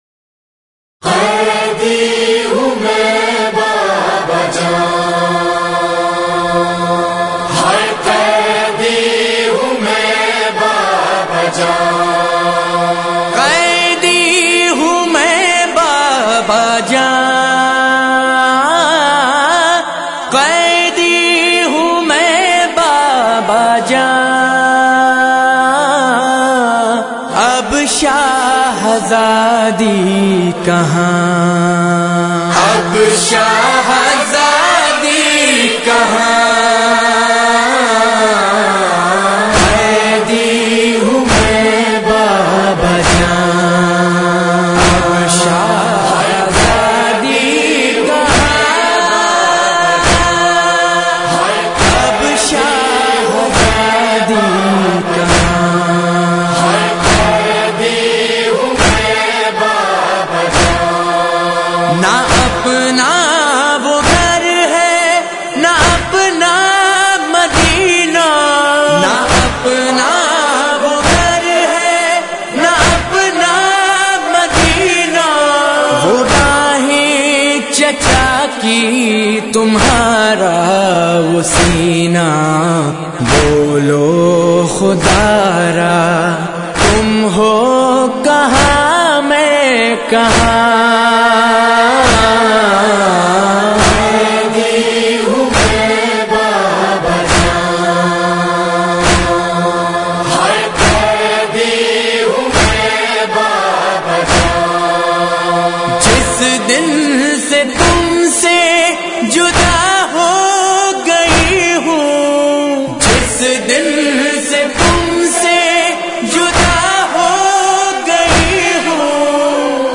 All Nohay